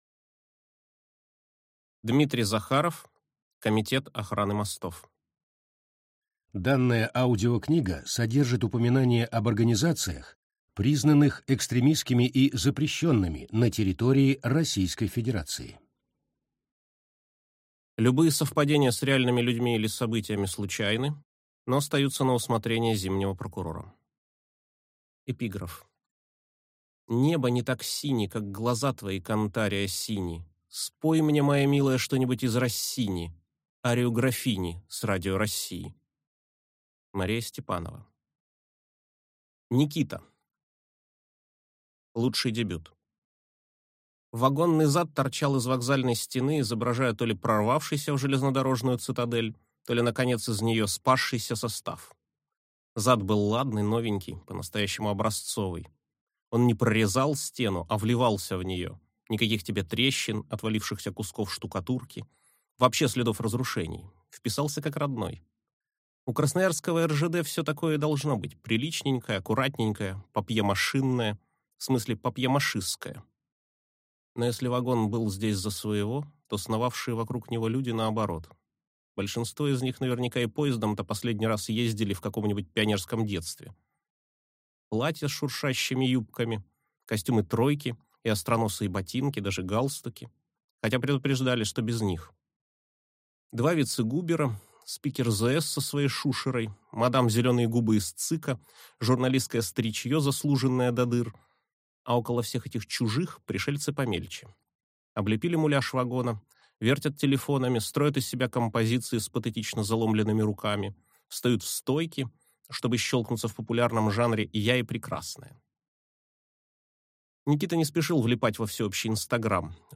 Аудиокнига Комитет охраны мостов | Библиотека аудиокниг